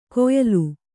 ♪ koyalu